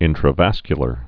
(ĭntrə-văskyə-lər)